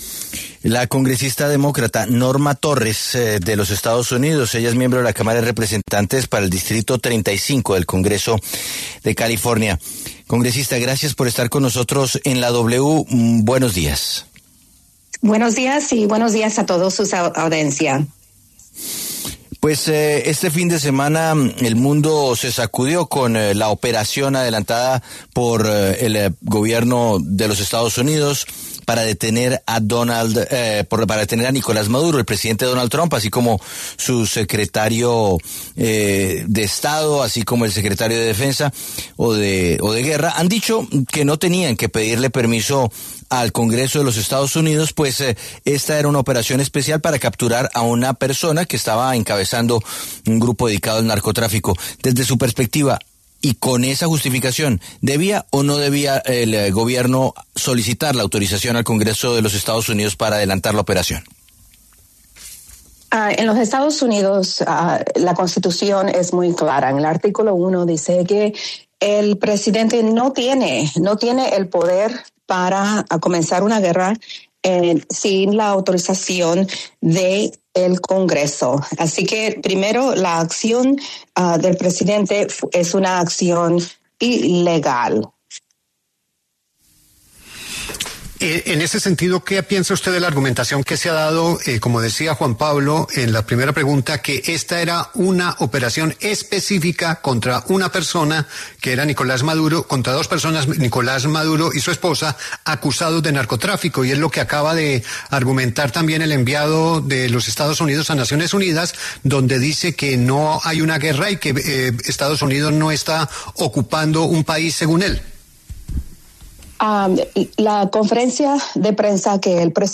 Norma Torres, congresista demócrata y miembro de la Cámara de Representantes de los Estados Unidos para el distrito 35 del Congreso de California, pasó por los micrófonos de La W para hablar sobre la captura de Nicolás Madura, de la cual, Trump no habría pedido autorización al Congreso.